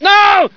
scream13.ogg